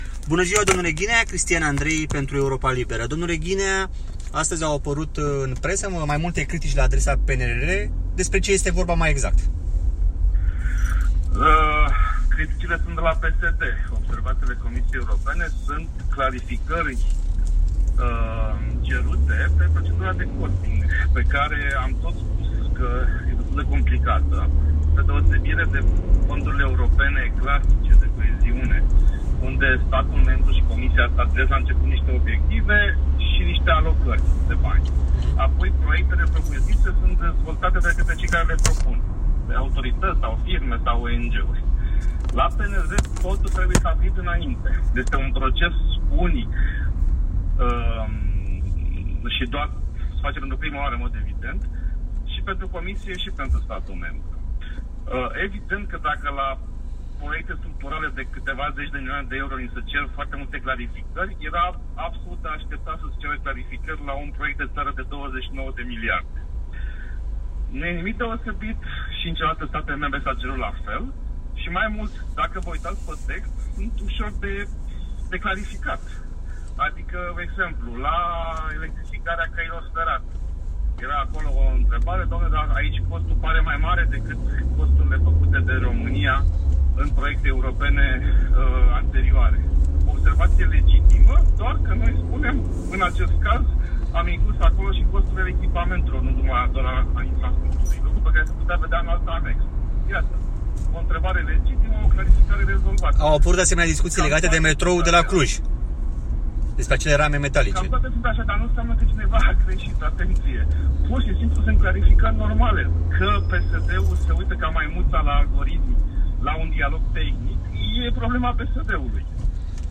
Interviu | Cum răspunde Ministrul Ghinea obiecțiilor aduse PNRR: „Dacă va fi respins, îmi dau demisia”.